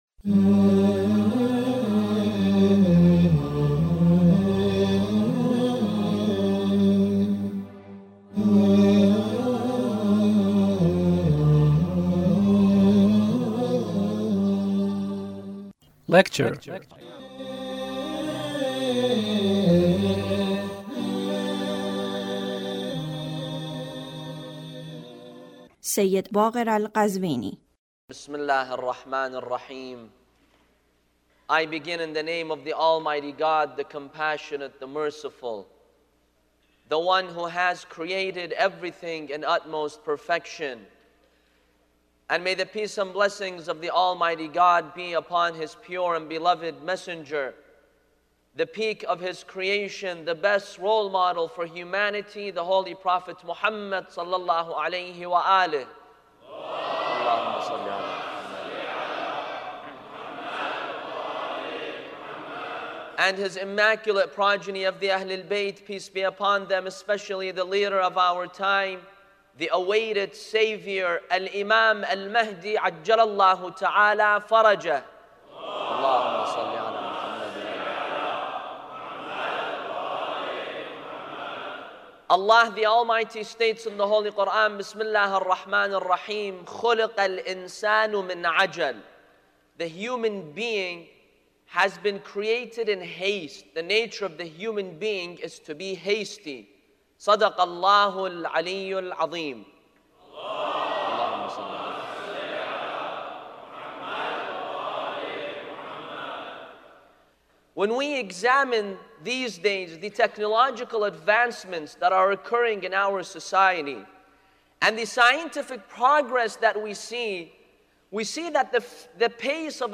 Lecture (1)